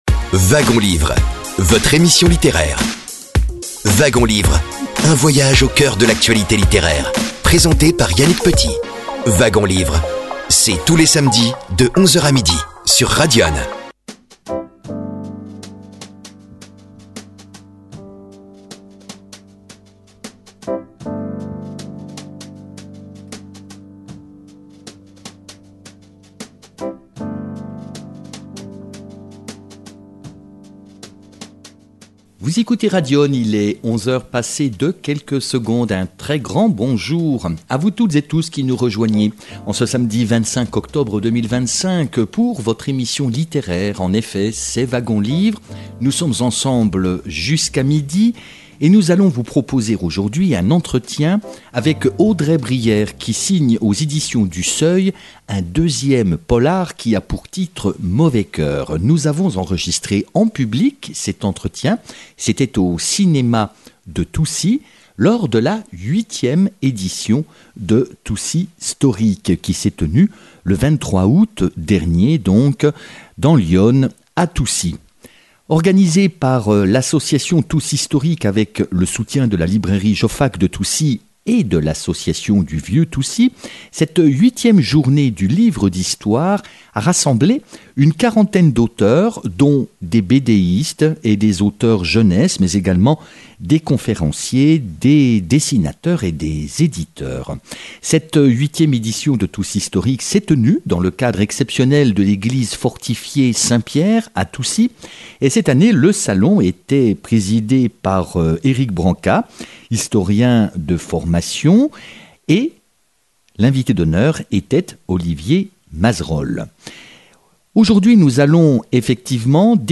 L’échange a été enregistré en public au cinéma de Toucy (89), lors de la 8e édition de Toucystoric, le 23 août 2025, journée du livre d’histoire.